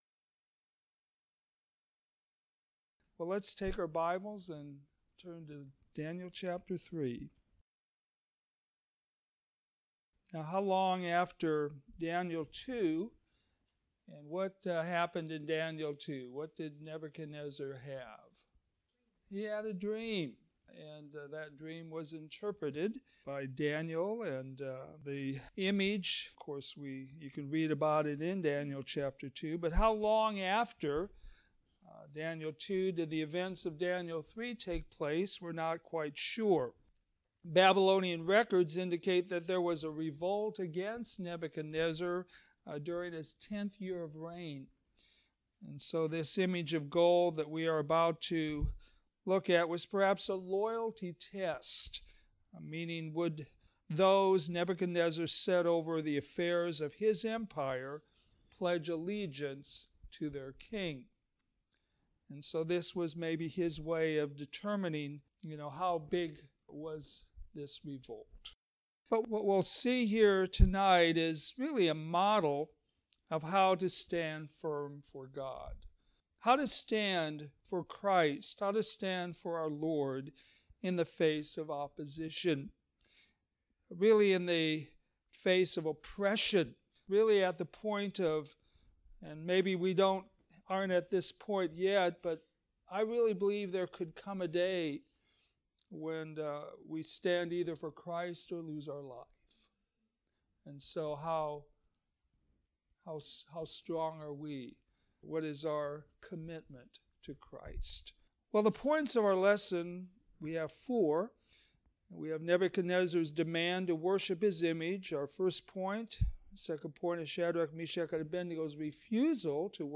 All Sermons - Westside Baptist Church
All sermons available in mp3 format